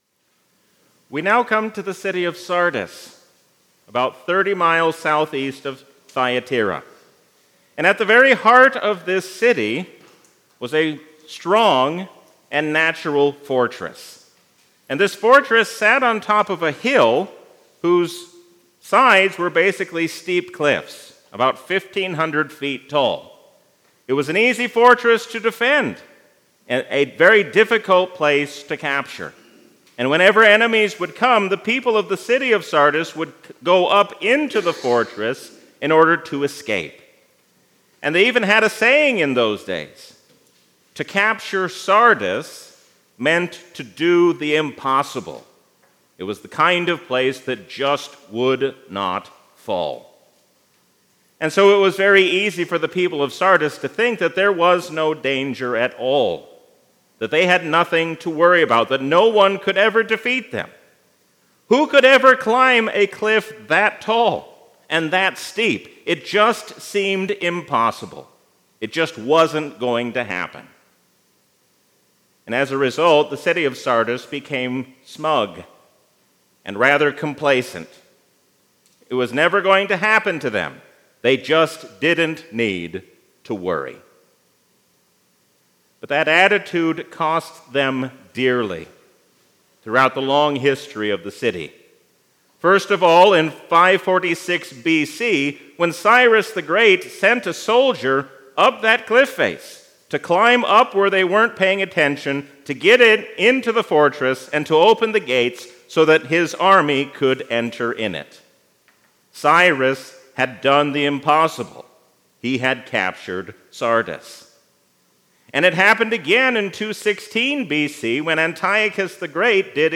A sermon from the season "Trinity 2023." We are called to faithfully carry out the work given to us while we wait for the Last Day.